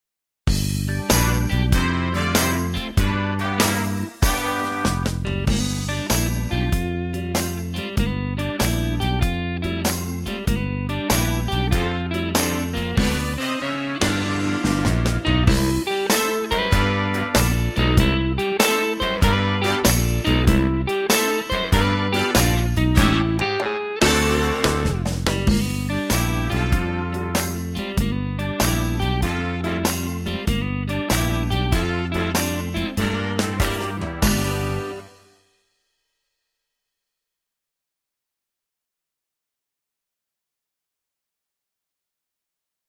VS Jitterbug (backing track)